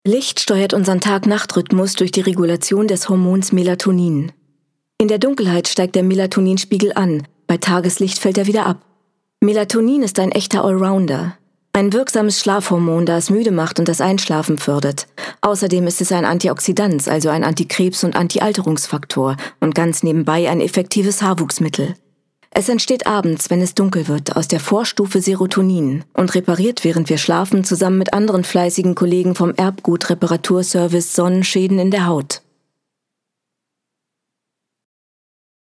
Markante warme, weiche Stimme mit Tiefe, die berührend und sinnlich sein kann.
Sprechprobe: Industrie (Muttersprache):